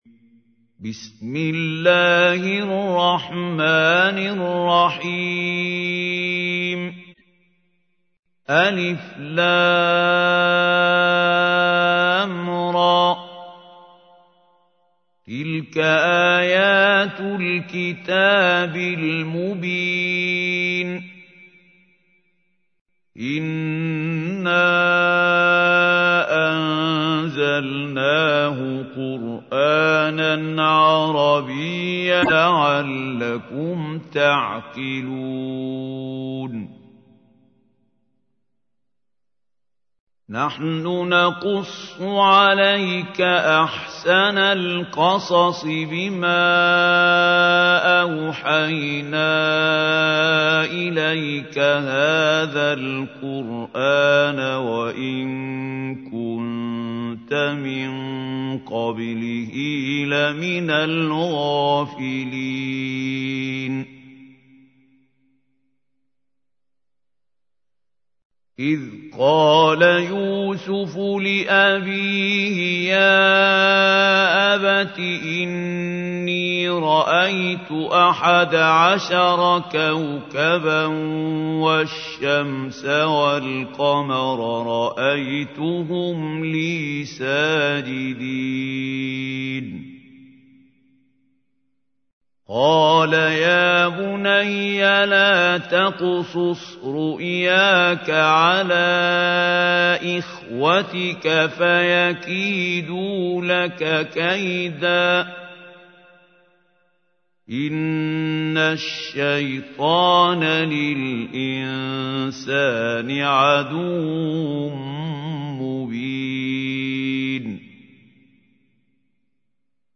تحميل : 12. سورة يوسف / القارئ محمود خليل الحصري / القرآن الكريم / موقع يا حسين